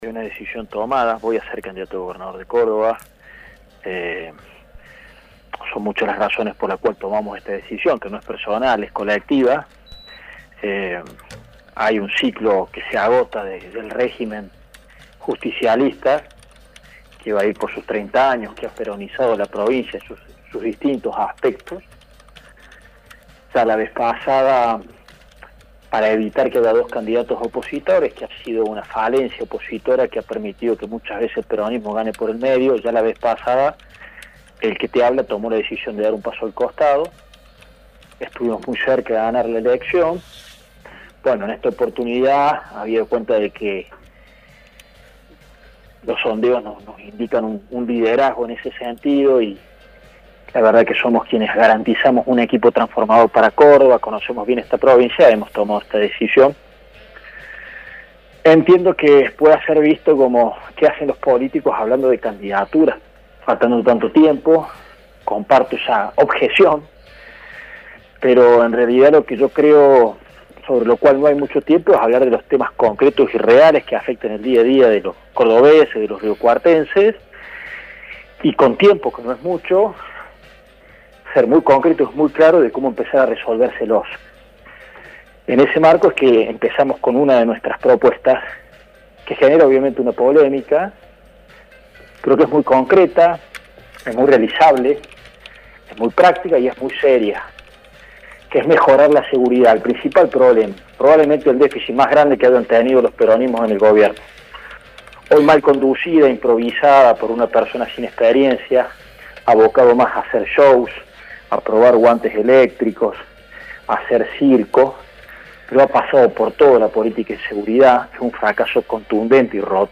En diálogo con Universidad, manifestó que la propuesta es mejorar la seguridad y la medida pondría a más de cuatro mil policías en las calles de las ciudades.